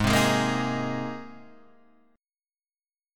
Abadd9 Chord